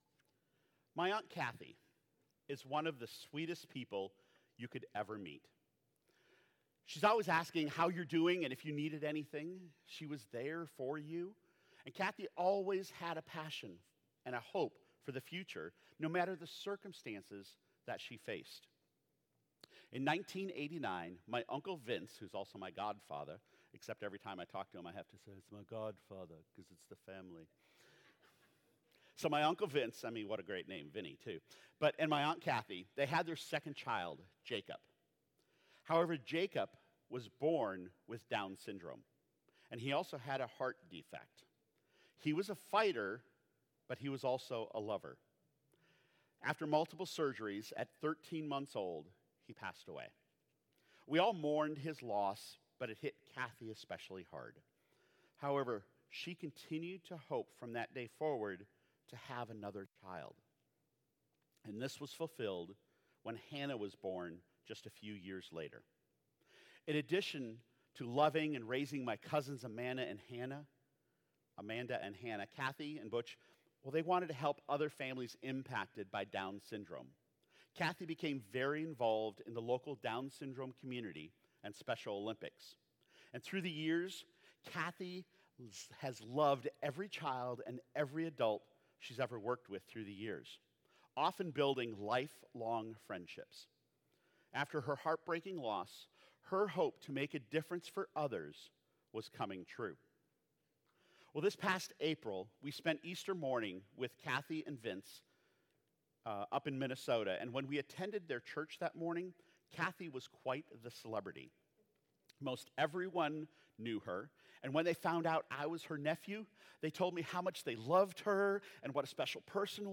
Sermons | Bridge City Church
Download Download Reference Luke 1:5-25 Guest Speaker